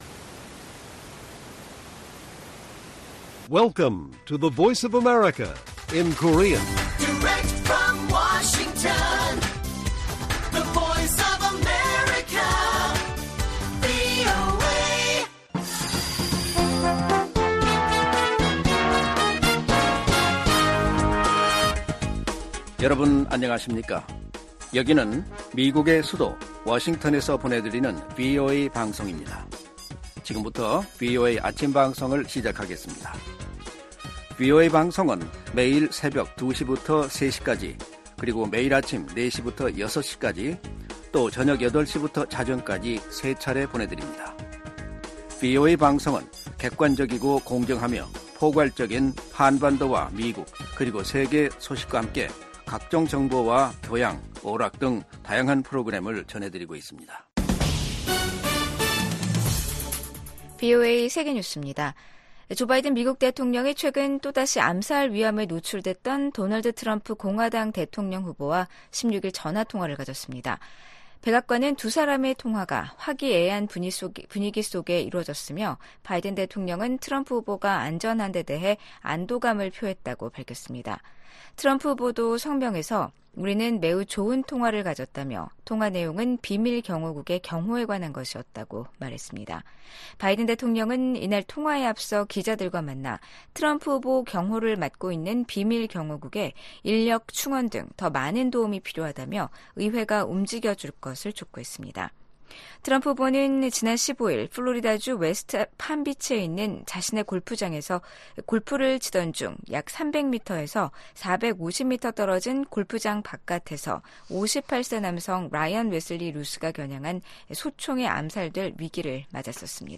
세계 뉴스와 함께 미국의 모든 것을 소개하는 '생방송 여기는 워싱턴입니다', 2024년 9월 18일 아침 방송입니다. 미국 공화당 대통령 후보인 도널드 트럼프 전 대통령을 암살하려고 시도한 사람이 사건 현장 주변에서 약 12시간 동안 머무른 것으로 드러났습니다. 블라디미르 푸틴 러시아 대통령이 현역 병력을 150만 명까지 늘리라고 지시했습니다. 홍콩에서 새로운 국가보안법에 따라 처음으로 유죄 판결을 받은 사례가 나왔습니다.